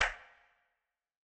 CLAP - ADDICTION.wav